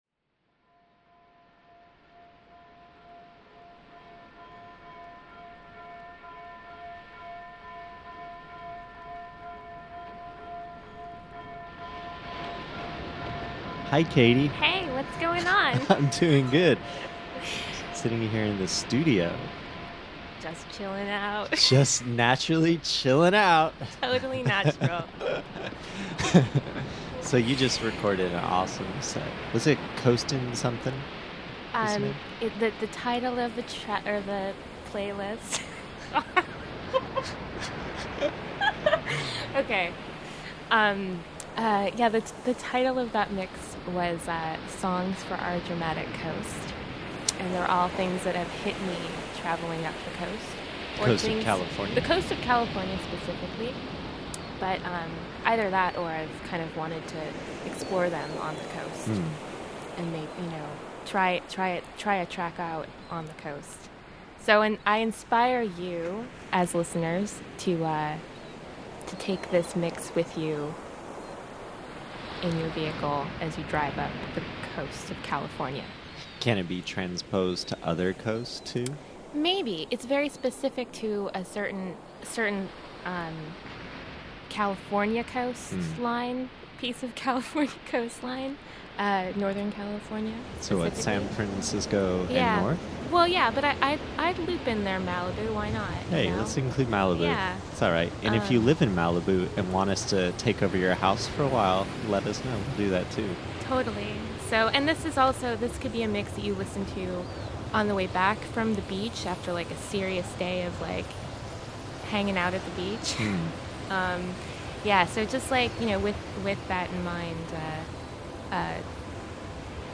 Ambient Avant-Garde Psych